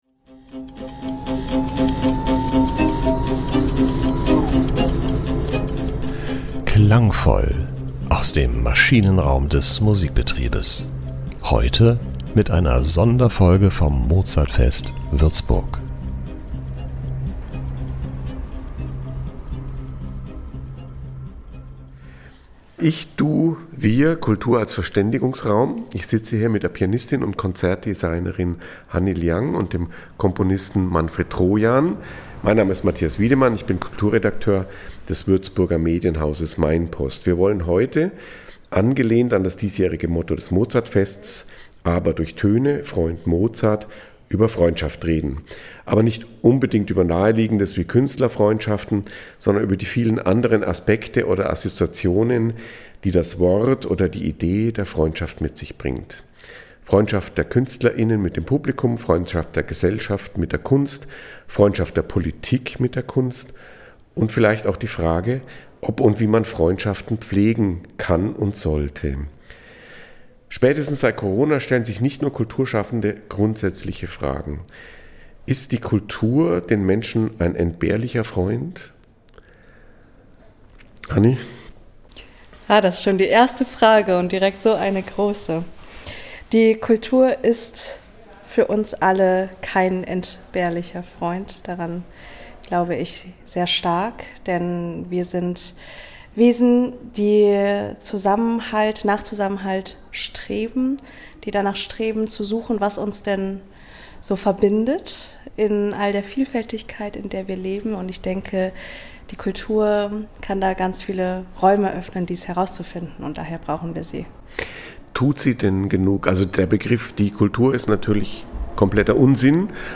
Eine spannende Diskussion über Nähe, Haltung, Erwartungen und die Zukunft des Musikbetriebs - mit vielen klugen Gedanken aus dem Maschinenraum der Klassik.